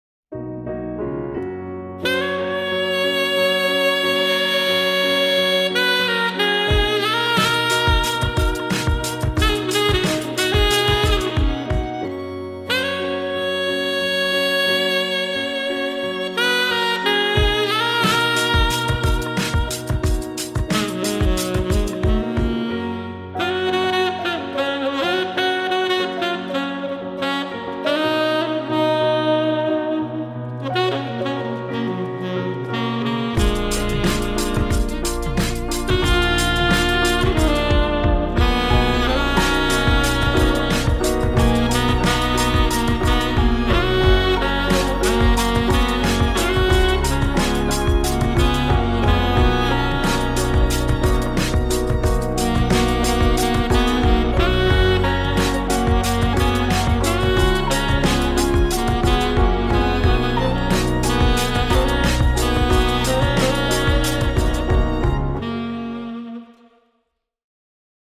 Take a powerhouse DJ, add some slick saxophone